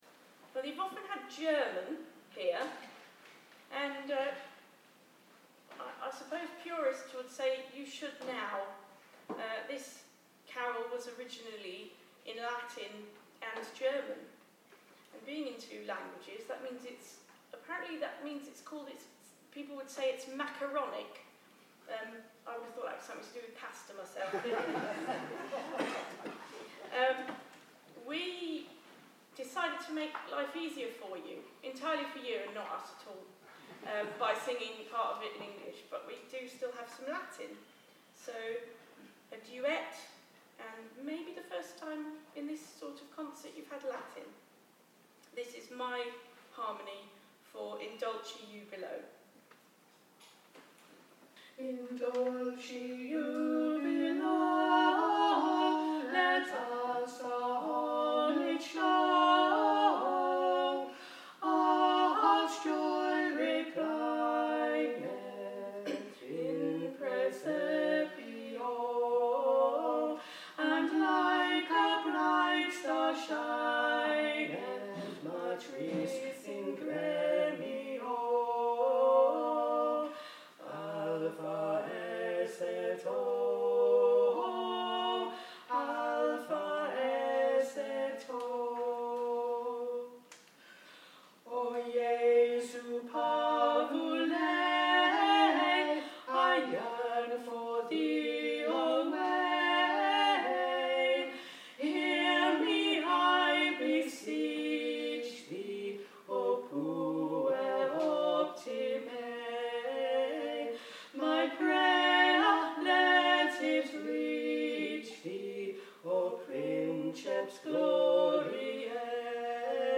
In Dulci Jubilo, two-part arrangement
This duet was part of the annual London Gallery Quire Christmas concert on 4 December 2024